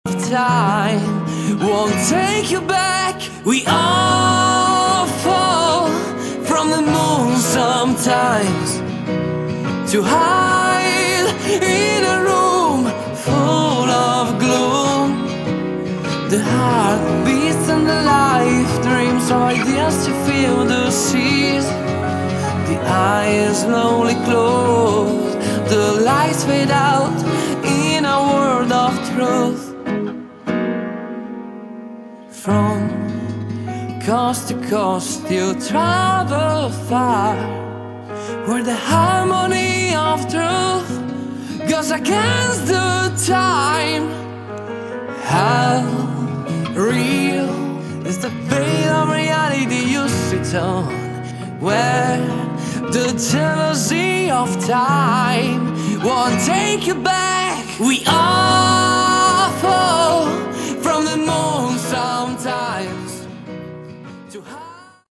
Category: Hard Rock
vocals
acoustic